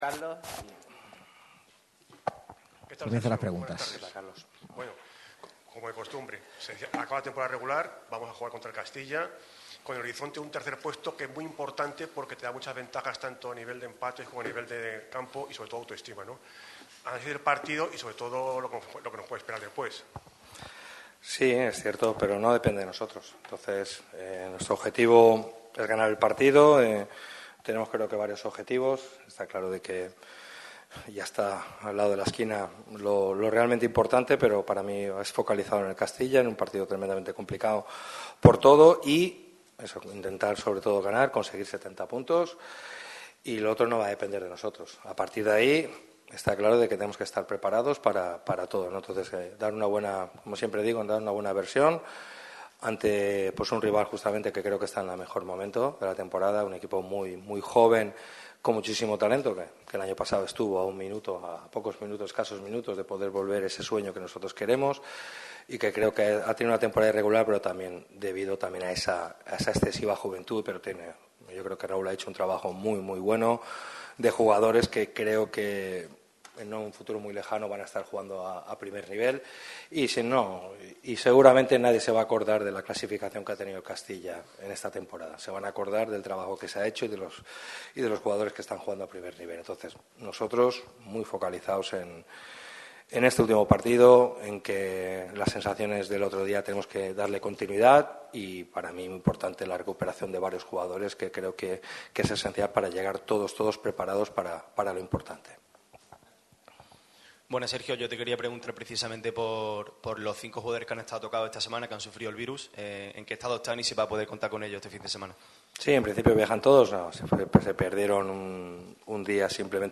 El técnico de Nules ha comparecido ante los medios en Valdebebas tras la victoria boquerona ante el filial del Real Madrid. El preparador analiza el estado del equipo, la victoria y ya pone la vista fija en los playoff que enfrentará al Málaga contra el Celta Fortuna.